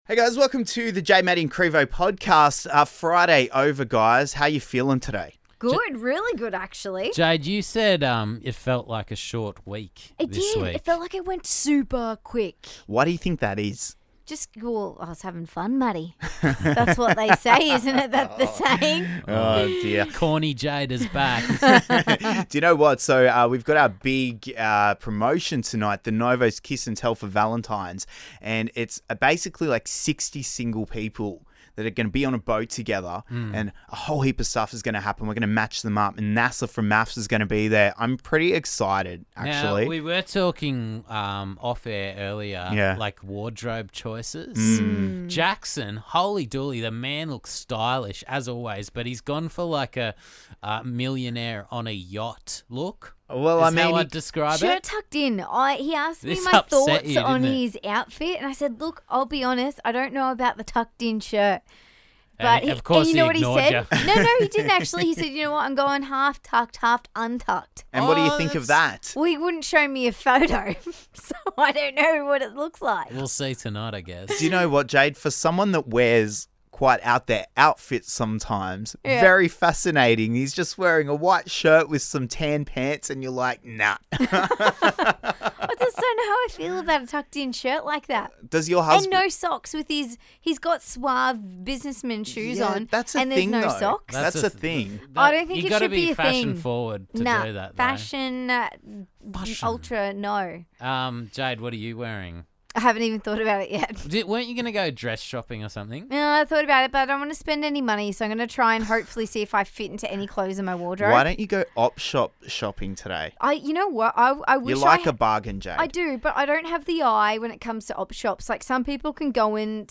We get a lizard into the studio to eat worms named after your EX.